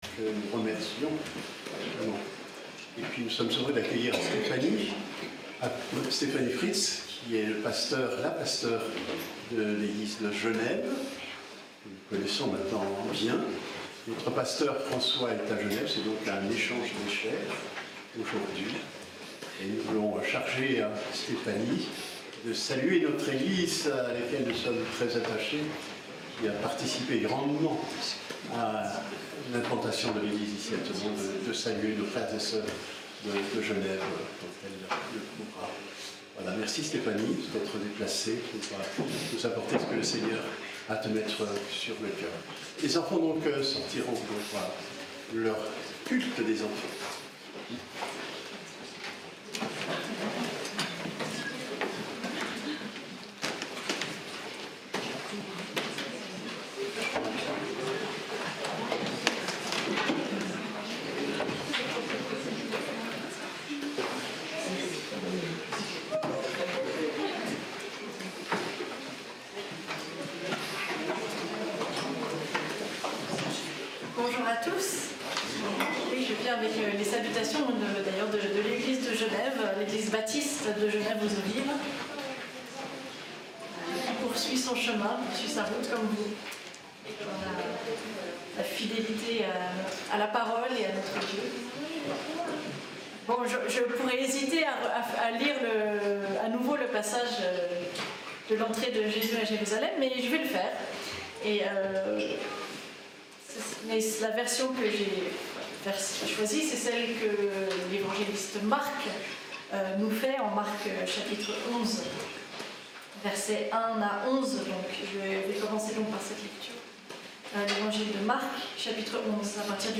(pasteure invitée) Texte biblique : Evangile de Marc chapitre 11 du verset 1 à 11 (L'entrée de Jésus à Jérusalem)